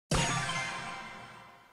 SFX_Button_Sound_Effrect.mp3